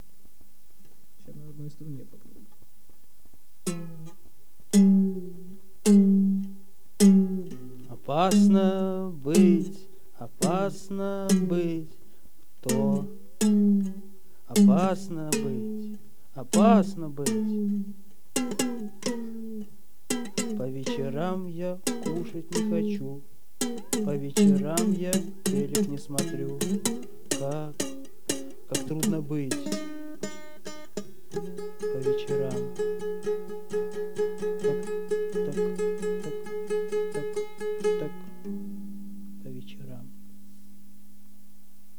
Мандолина и лежащий человек.